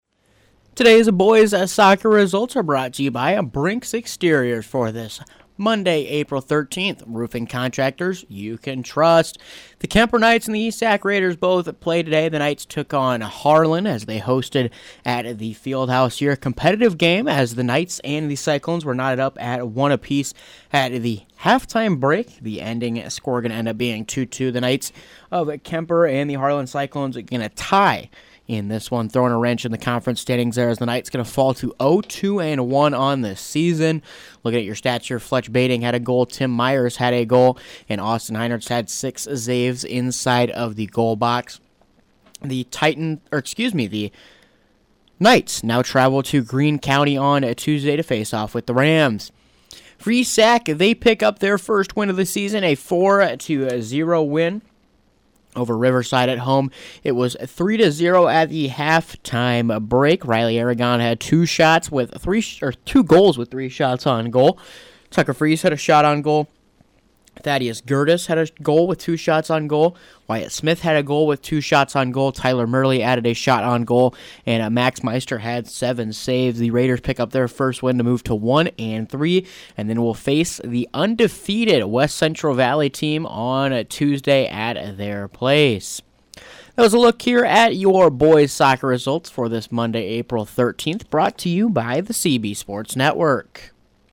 Below is an Audio Recap of Boys Soccer Results for Monday, April 13th